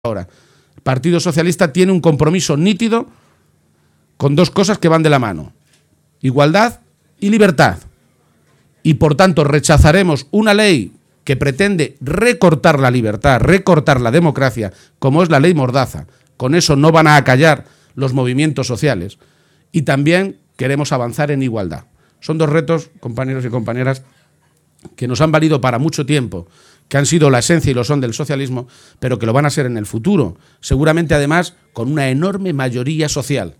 Audio Page acto JSE en Albacete-3